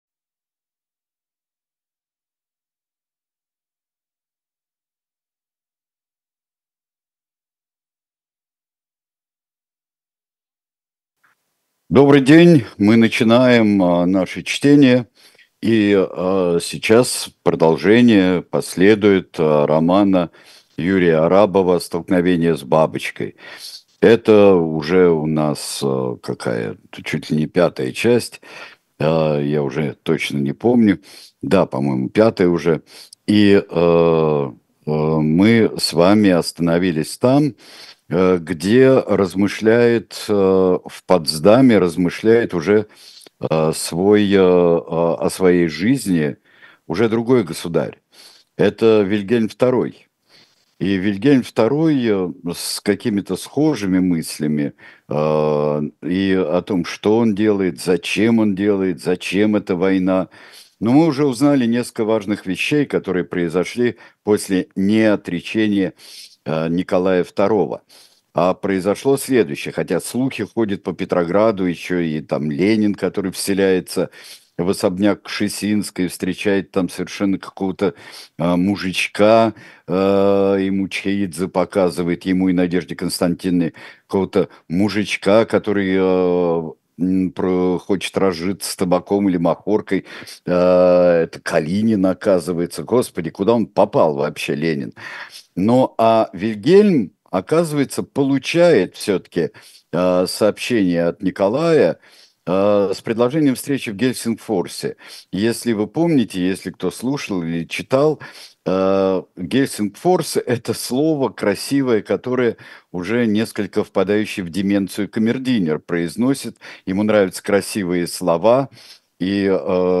Сергей Бунтман читает роман Юрия Арабова